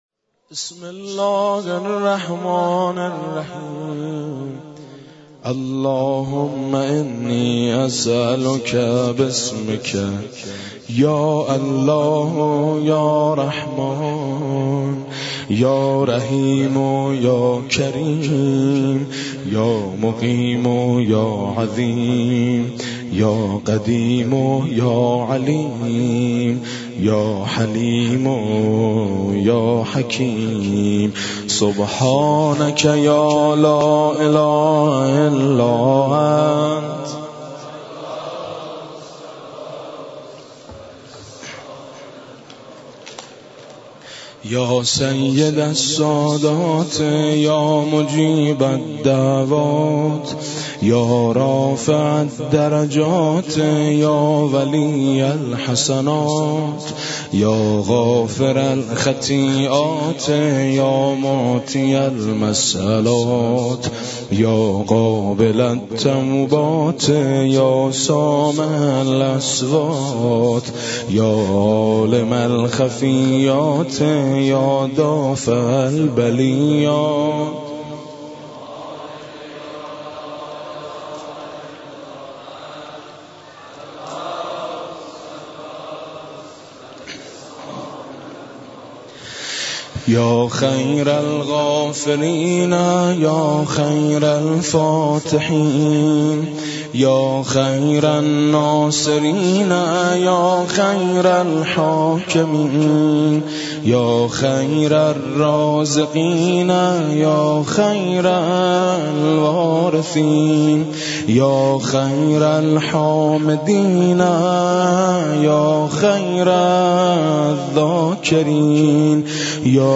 مراسم شب بیست و سوم ماه مبارک رمضان با مداحی حاج میثم مطیعی در مسجد جامع بازار تهران برگزار گردید.
دعای جوشن کبیر
روضه حضرت فاطمه زهرا (سلام الله علیها)